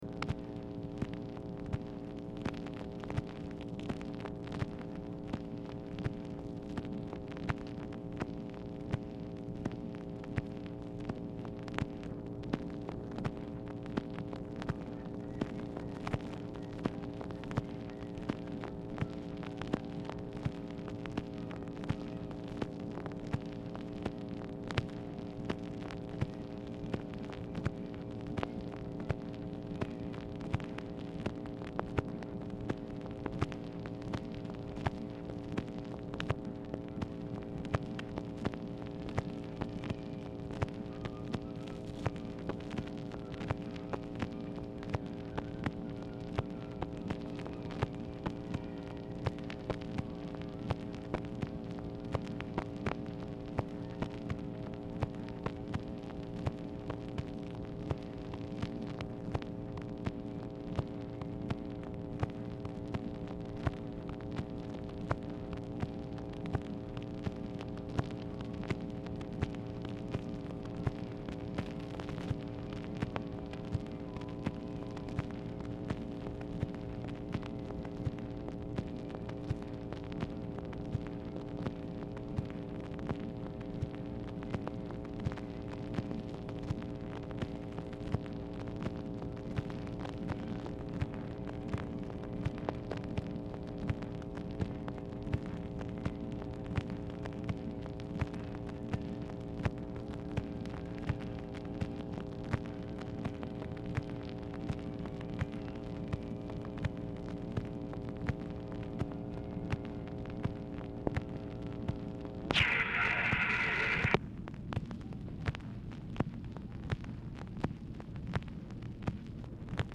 Telephone conversation # 5202, sound recording, OFFICE NOISE, 8/25/1964, time unknown | Discover LBJ
MUSIC AUDIBLE IN BACKGROUND
Format Dictation belt
Location Of Speaker 1 Oval Office or unknown location